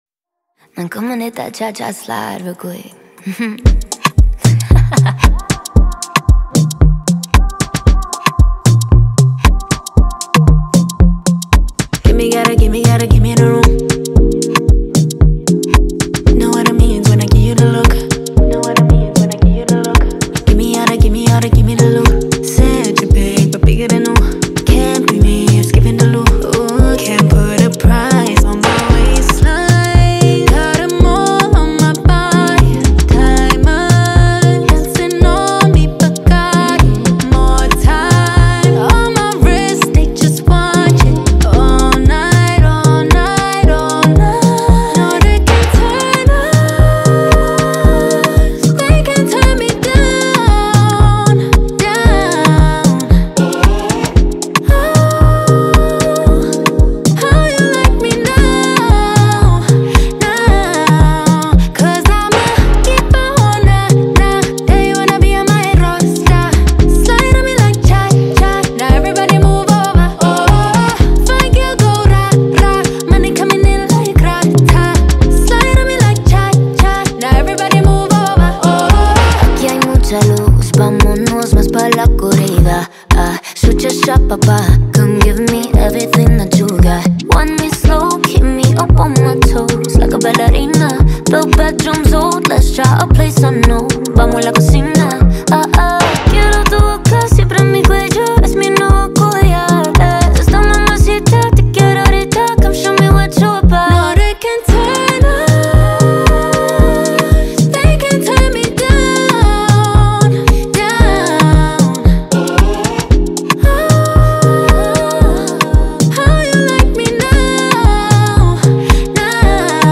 AfroBeats | AfroBeats songs
powerhouse vocals
seductive and unforgettable tunes